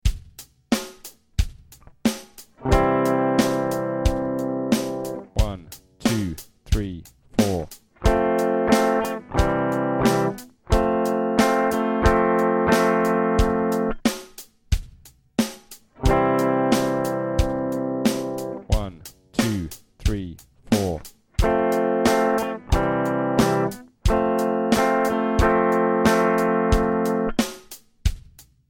These are a 2 bar chord sequence of I, IV, V chords in any combination (C, F G in the key of C).  Each test will play the tonic (I) chord (C) before the sequence.